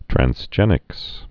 (trăns-jĕnĭks, trănz-)